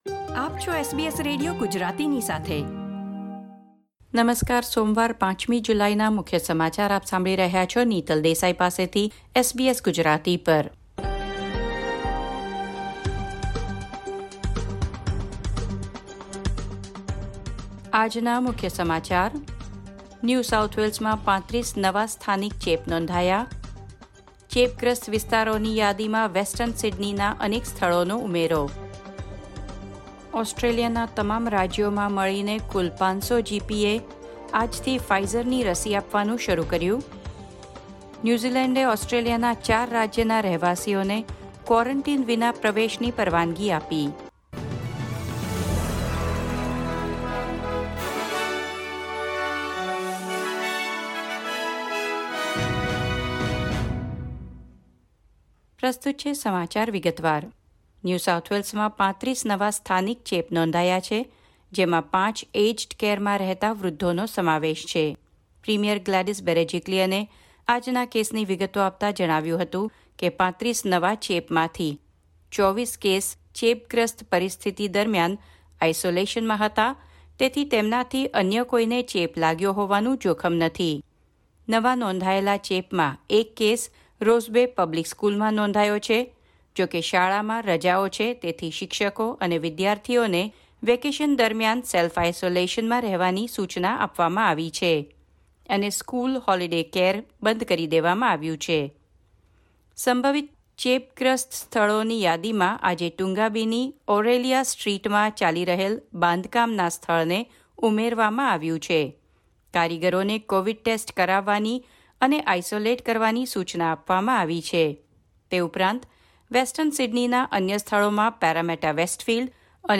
SBS Gujarati News Bulletin 5 July 2021
gujarati_0507_newsbulletin.mp3